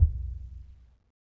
BDrumNewhit_v1_rr2_Sum.wav